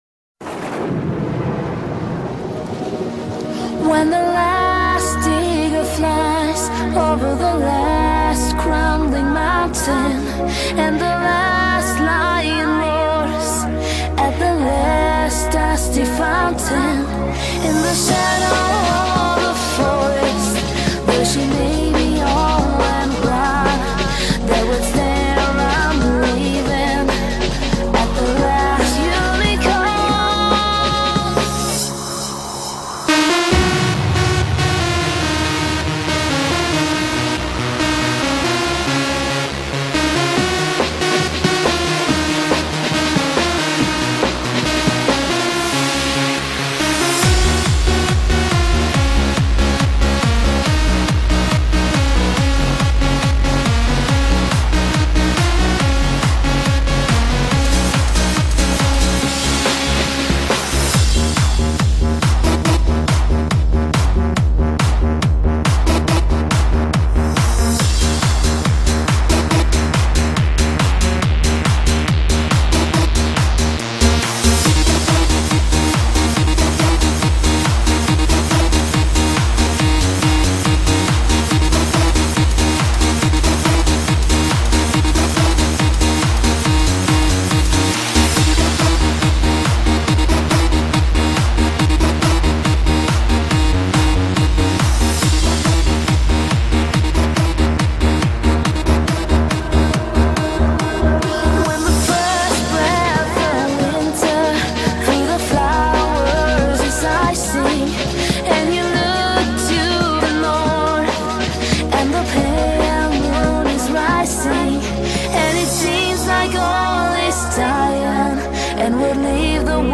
BPM70-140
Audio QualityCut From Video